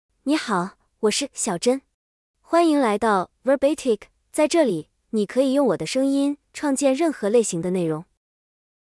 Xiaozhen — Female Chinese (Mandarin, Simplified) AI Voice | TTS, Voice Cloning & Video | Verbatik AI
Xiaozhen is a female AI voice for Chinese (Mandarin, Simplified).
Voice sample
Listen to Xiaozhen's female Chinese voice.
Female
Xiaozhen delivers clear pronunciation with authentic Mandarin, Simplified Chinese intonation, making your content sound professionally produced.